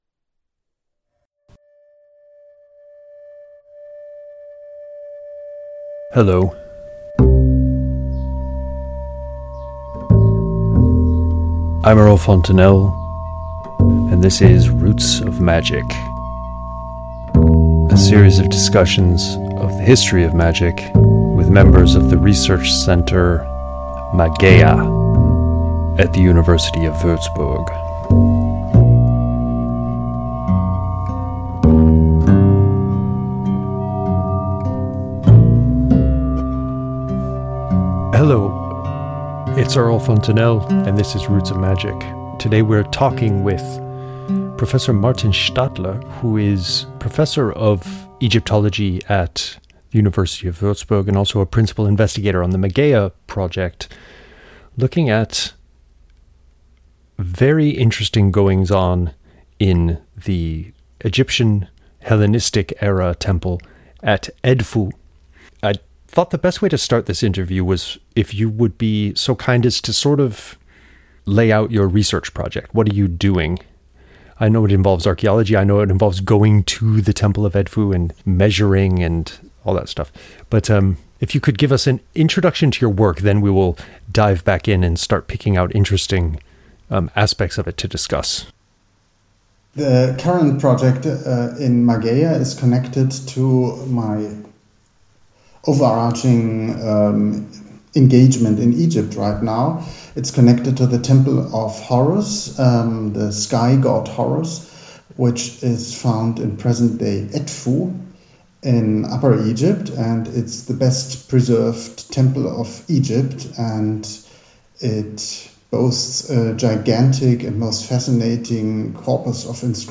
Roots of Magic Interview 9